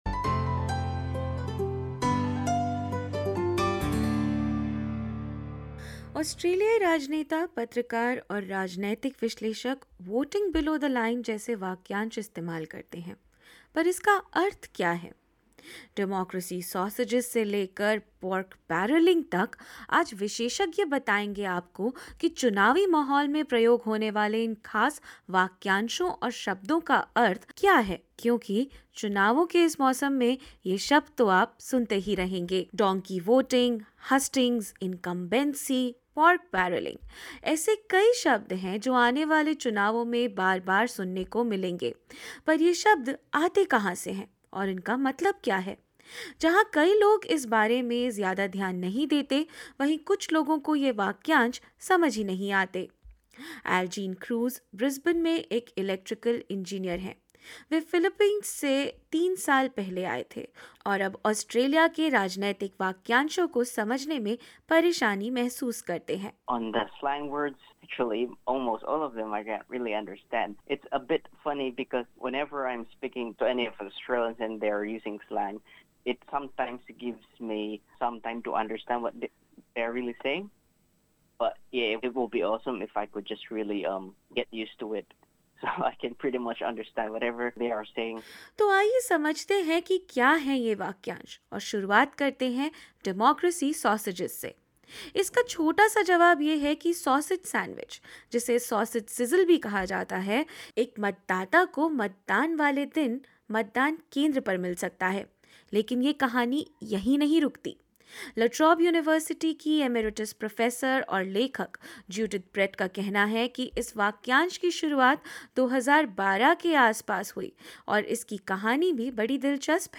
इस रिपोर्ट में ऐसे ही कुछ दिलचस्प वाक्यांशों को समझेंगे आज विशेषज्ञों की मदद से, ताकि इस चुनाव के मौसम, आप खबरों और बहसों को पूरा समझ सकें।